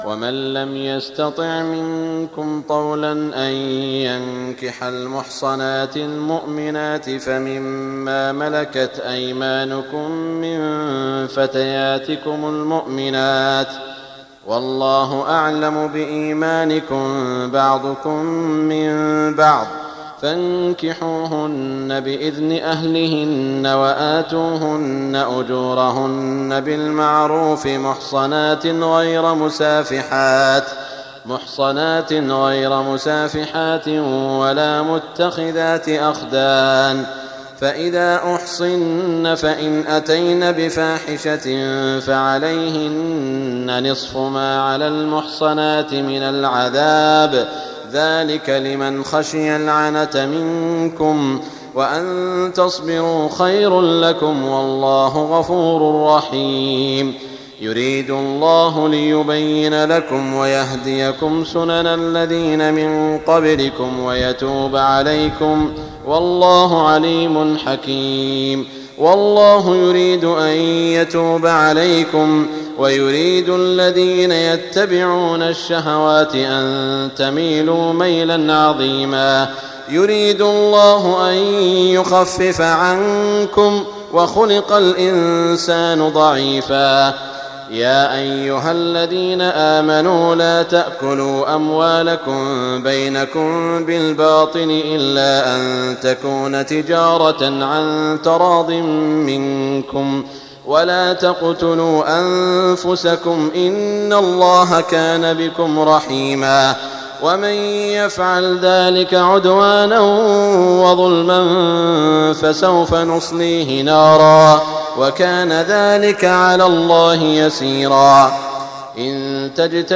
تهجد ليلة 25 رمضان 1419هـ من سورة النساء (25-99) Tahajjud 25 st night Ramadan 1419H from Surah An-Nisaa > تراويح الحرم المكي عام 1419 🕋 > التراويح - تلاوات الحرمين